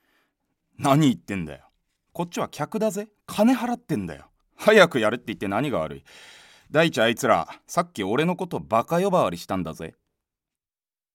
セリフB
ボイスサンプル